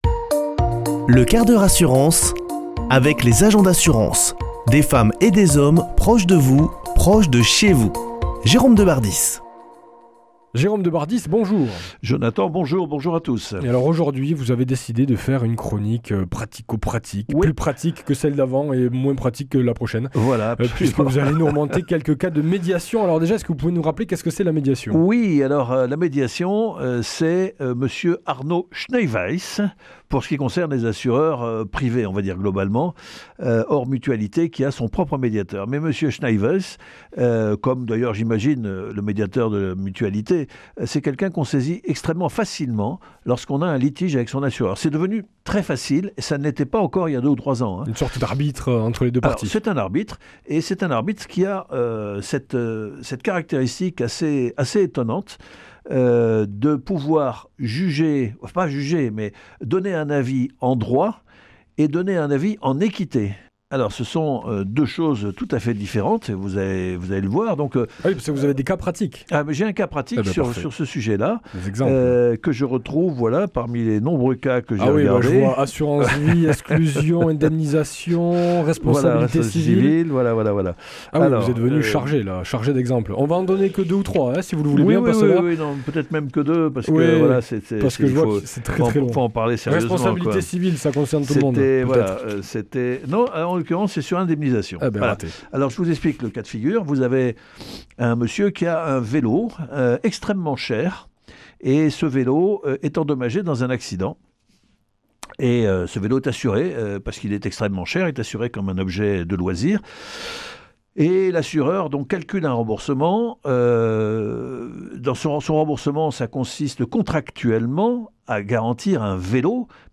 mardi 20 janvier 2026 Chronique le 1/4 h assurance Durée 5 min
Chroniqueur